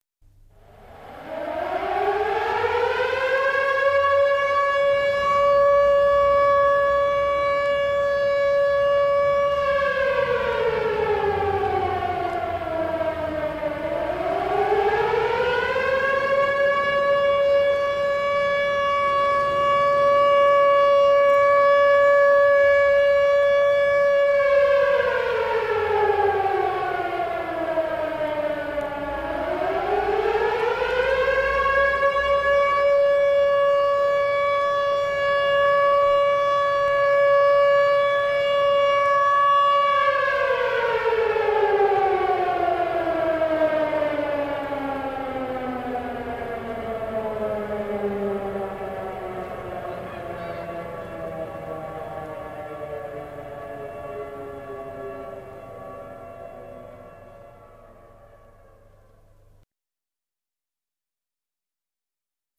Eigentlich hätte der Ton "Warnung der Bevölkerung" zu hören sein müssen. Es ist ein einminütiger auf- und abschwellender Ton. Er warnt die Bevölkerung vor akuten Gefahren wie Unwetter oder Großbränden.